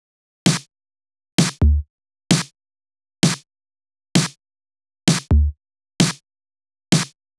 VDE 130BPM Change Drums 5.wav